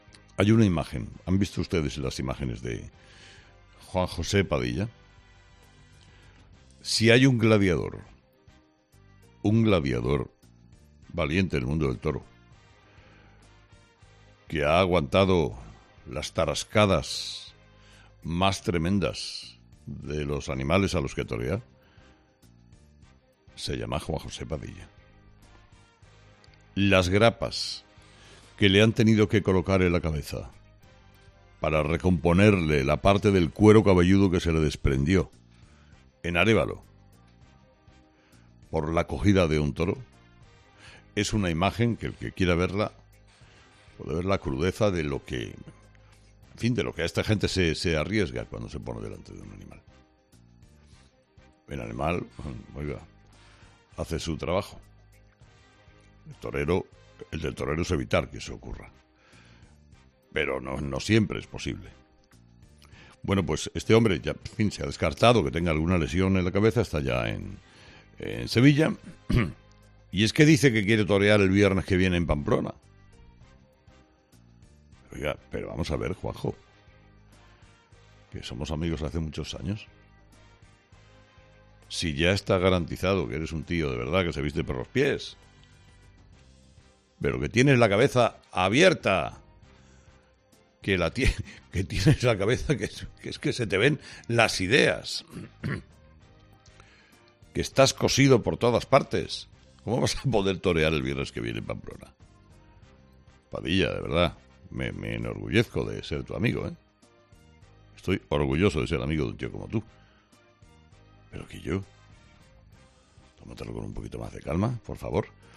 El emotivo mensaje de Herrera a Juan José Padilla
Herrera, como " orgulloso amigo" del diestro, le ha querido dar una recomendación durante uno de sus monólogos de este lunes en 'Herrera en COPE'.